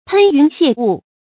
噴云泄霧 注音： ㄆㄣ ㄧㄨㄣˊ ㄒㄧㄝ ˋ ㄨˋ 讀音讀法： 意思解釋： 形容云靄繚繞山岳的景象。